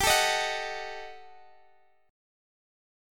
Listen to Ebdim/G strummed